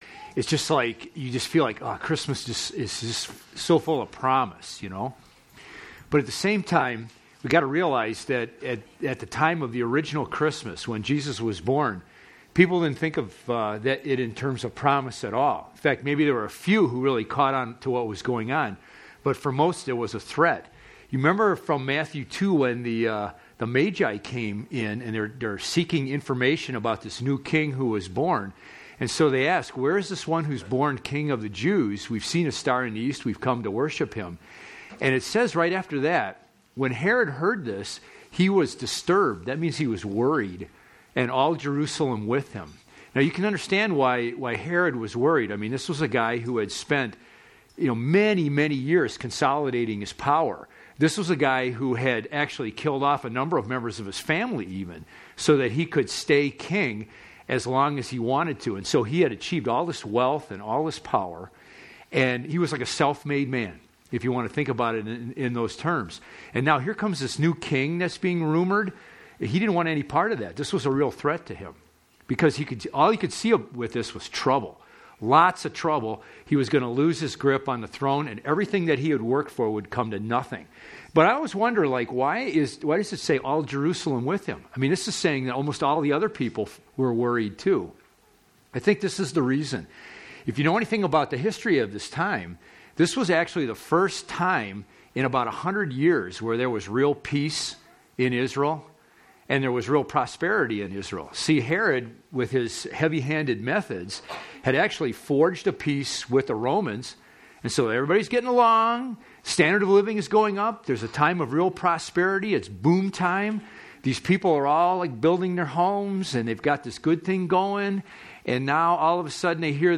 Find a previous sermon | Subscribe to COH's Sermon Podcast